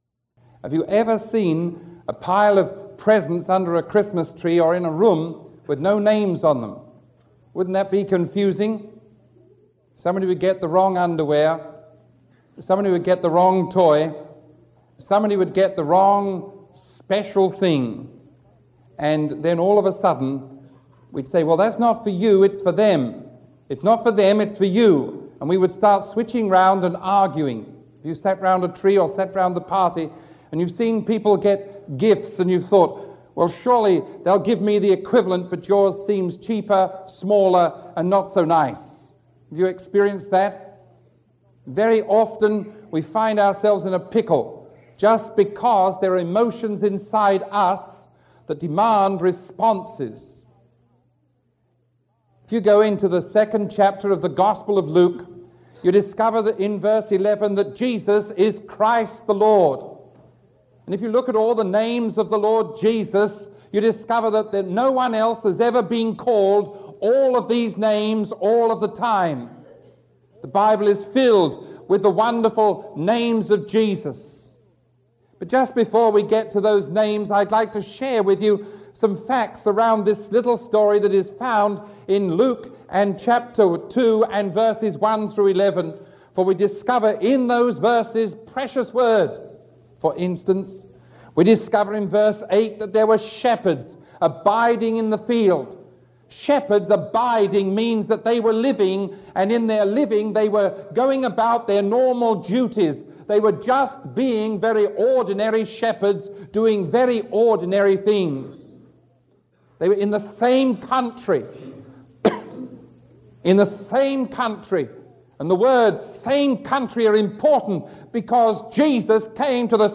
Sermon 0958A recorded on December 23